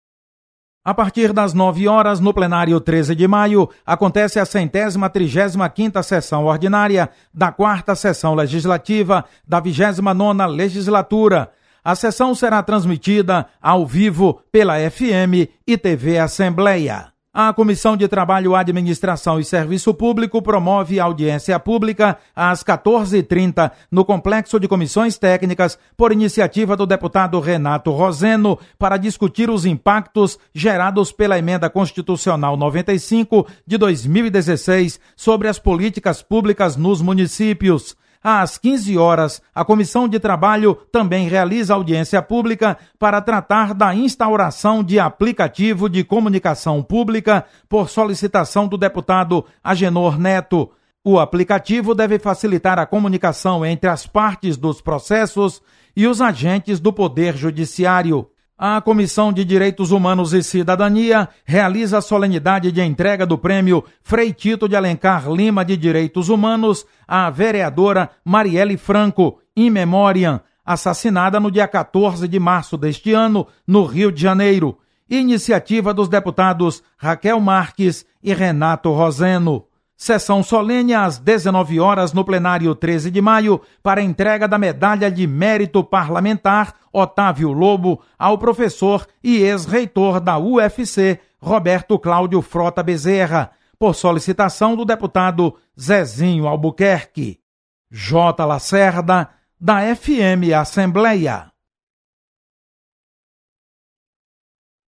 Acompanhe as atividades desta terça-feira na Assembleia Legislativa. Repórter